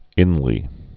(ĭnlē)